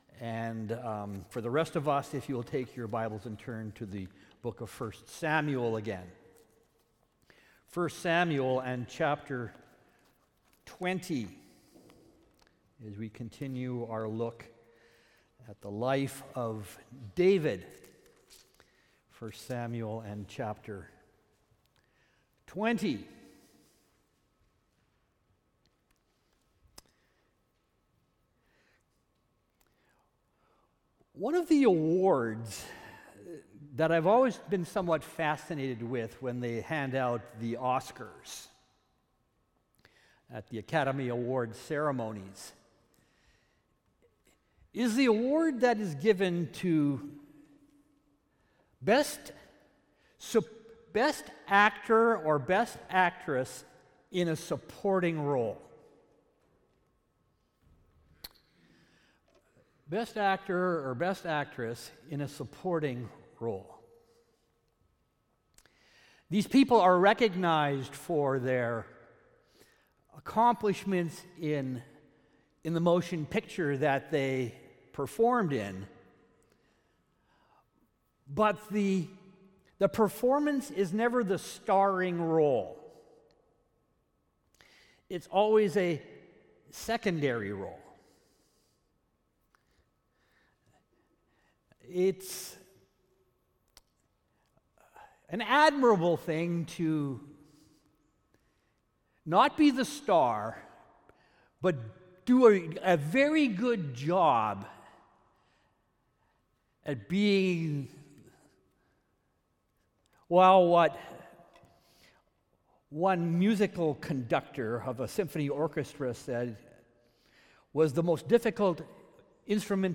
Sermons | Richmond Alliance Church